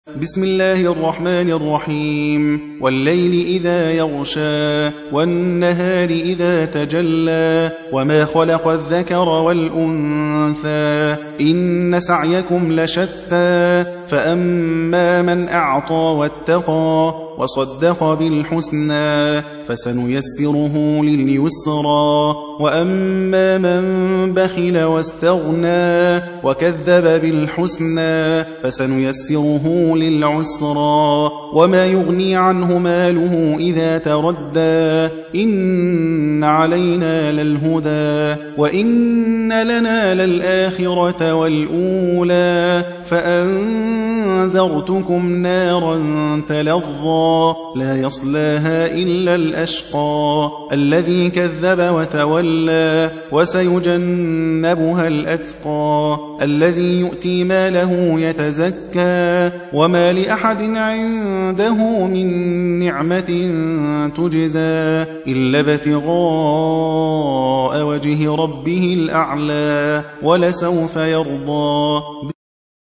92. سورة الليل / القارئ